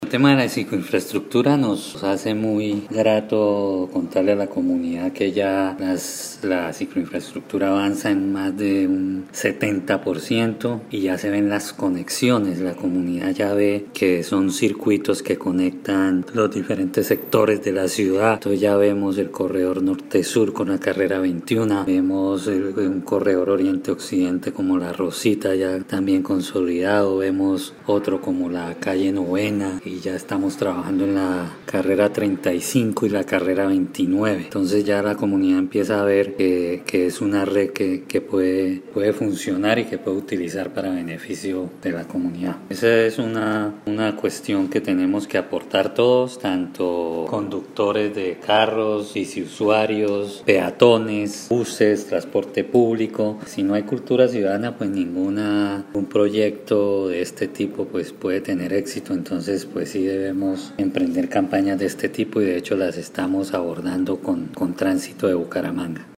Descargue audio: Iván Vargas, secretario de Infraestructura